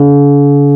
KEY RHODS 0B.wav